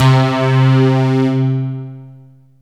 SYNTH LEADS-1 0001.wav